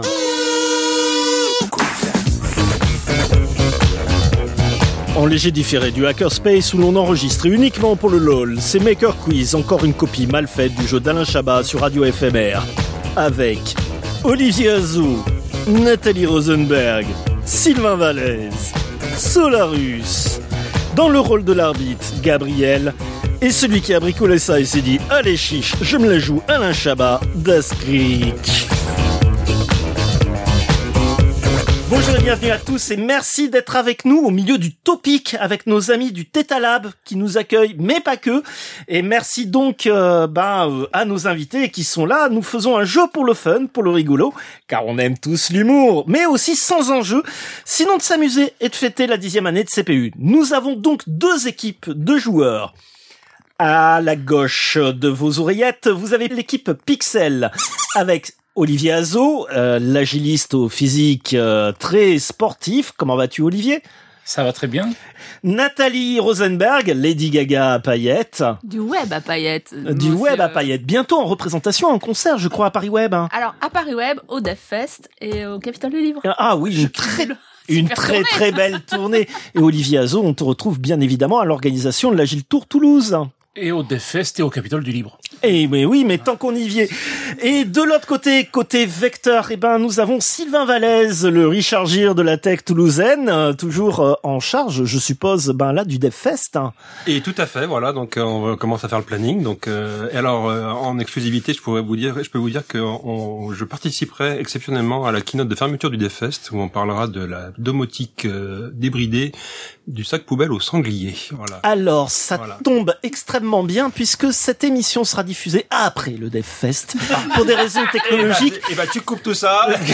[Générique] En léger différé du hackerspace où l'on enregistre, et uniquement pour le lol, c'est MakerQuiz.